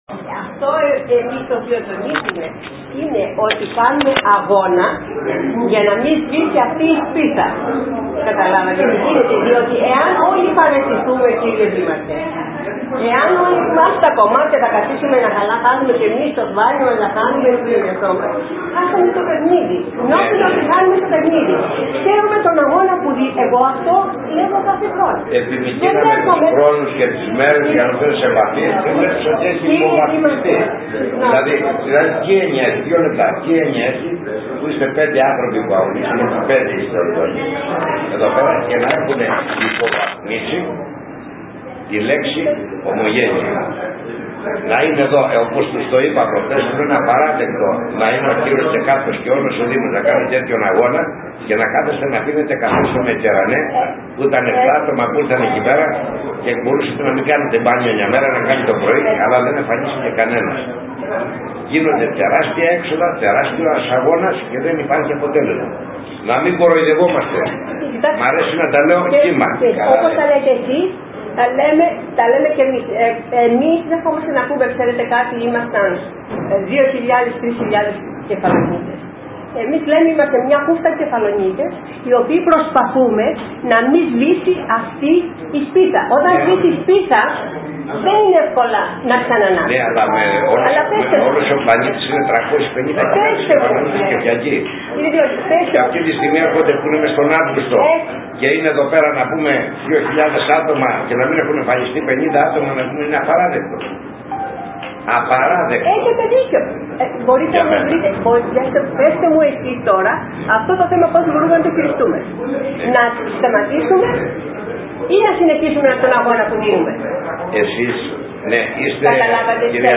Αιφνιδίασε τους πάντες ο Δήμαρχος με την επίθεση που εξαπέλυσε σ’ αυτούς που σνομπάρουν τις εκδηλώσεις των Αποδήμων. Όπως είπε προτιμούν να πάνε στις καφετέριες και τις παραλίες παρά να διαθέσουν λίγες ώρες για το τριήμερο συνέδριο της; “Εβδομάδας των Αποδήμων”